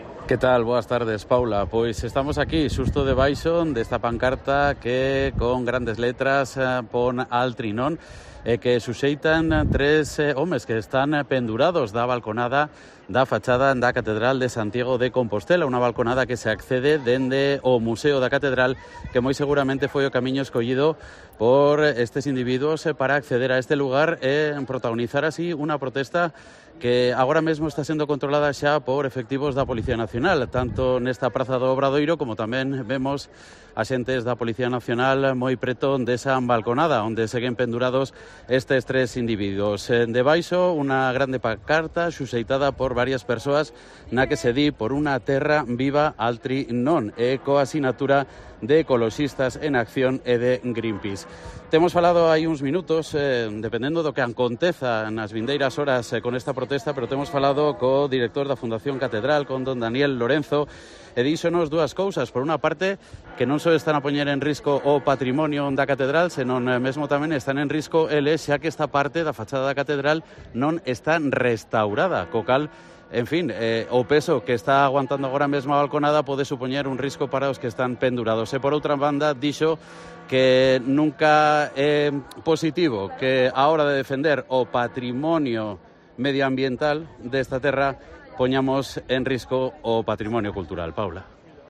desde la Plaza del Obradoiro